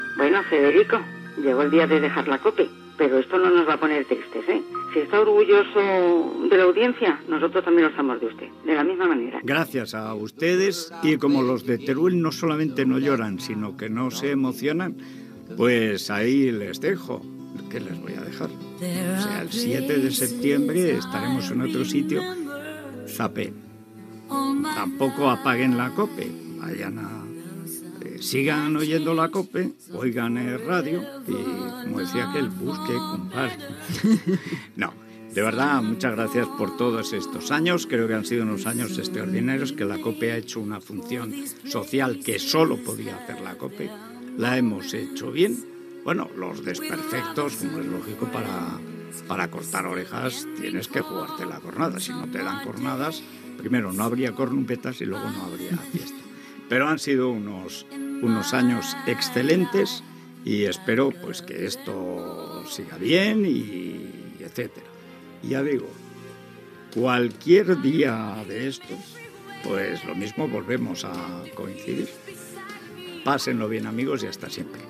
Trucada d'una oïdora de comiat, paraules finals per tancar el programa i anunciar a l'audiència que a partir del setembre Jiménez Losantos estarà en una altra emissora (esRadio)
Info-entreteniment
FM